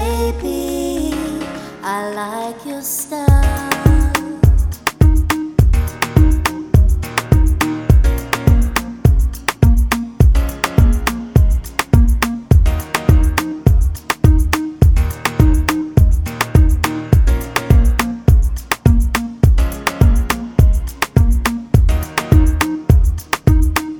for duet R'n'B / Hip Hop 2:54 Buy £1.50